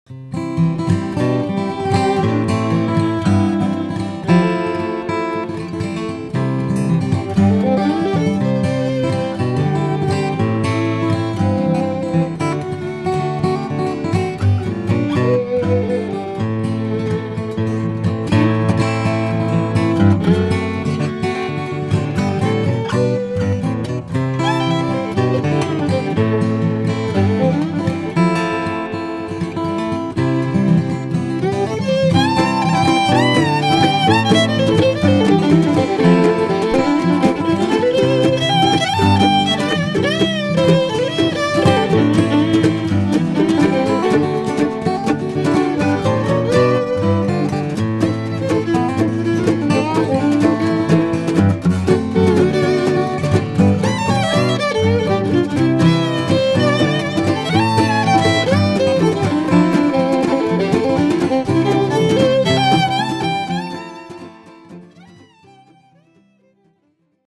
songs and instrumentals
guitar